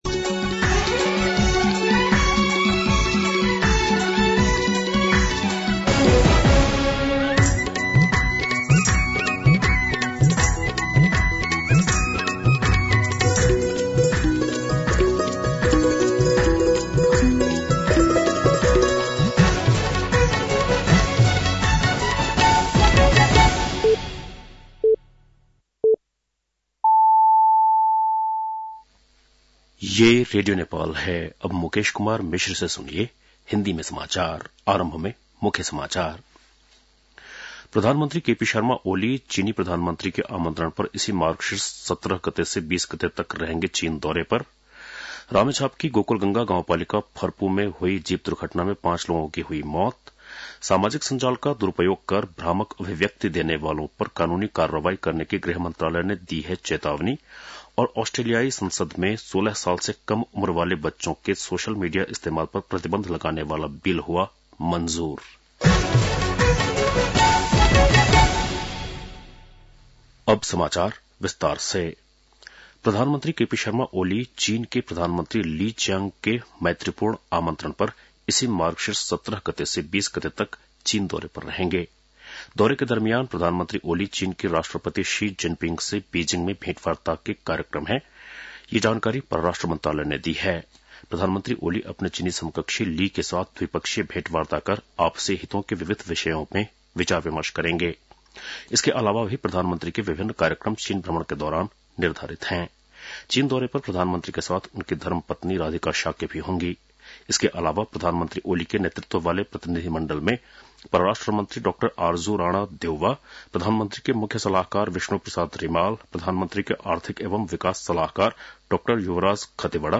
बेलुकी १० बजेको हिन्दी समाचार : १६ मंसिर , २०८१
10-PM-Hindi-News-8-14.mp3